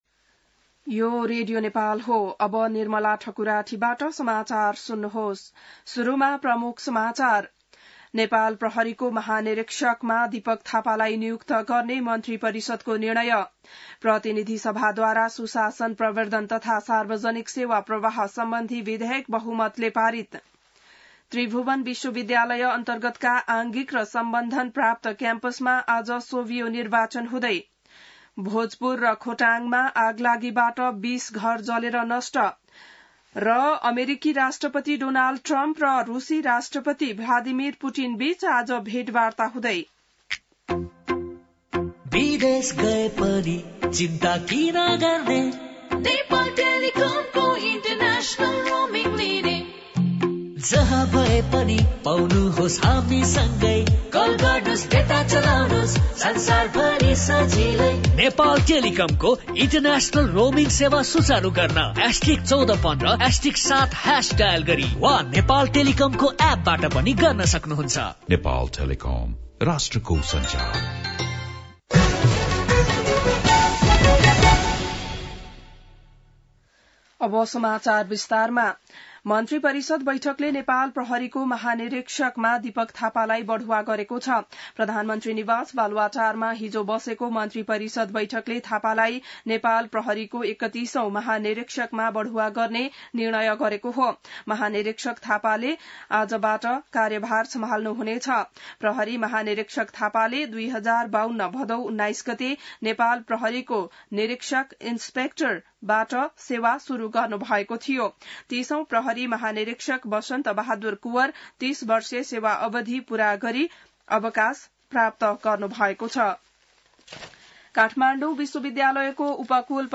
बिहान ७ बजेको नेपाली समाचार : ५ चैत , २०८१